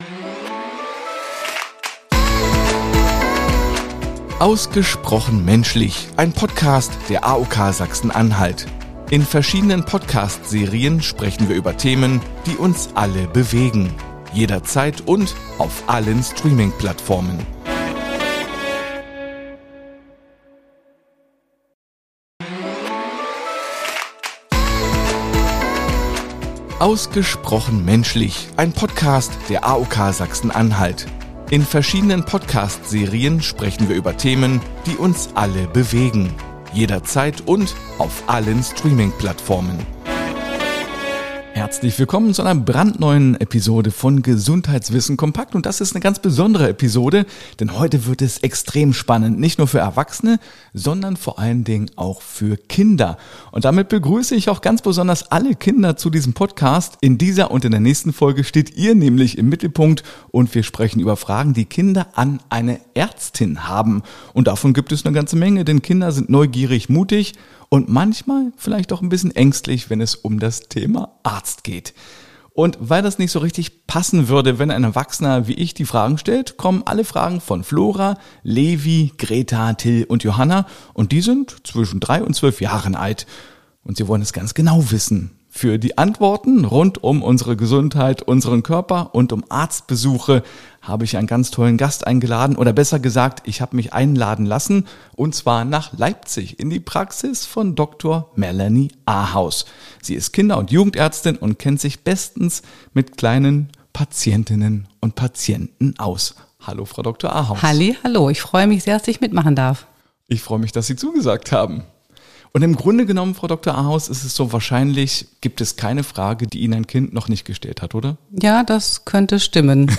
Fünf Kinder zwischen 3 und 12 Jahren stellen Ihre ganz persönlichen Fragen an eine Kinder- und Jugendärztin und kitzeln allerlei spannende Antworten aus ihr heraus.